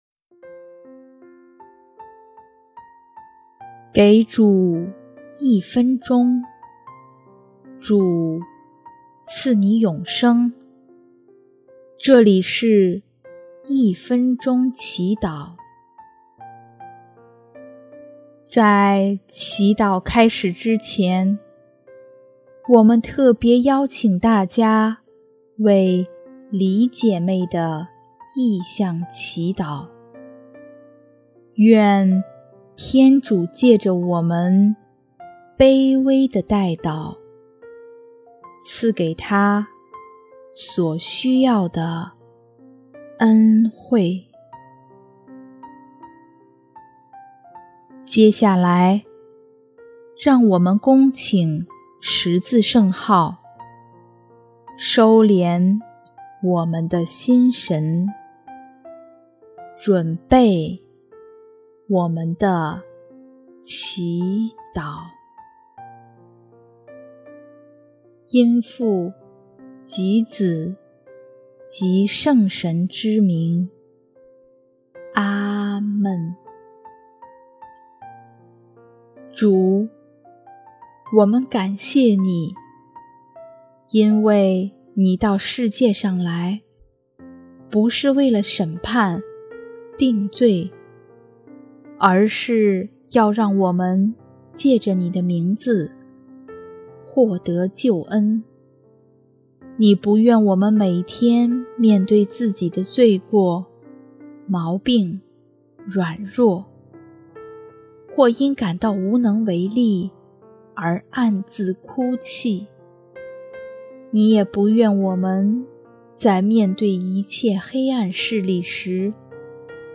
【一分钟祈祷】| 4月19日 一切赞美归于伟大的主耶稣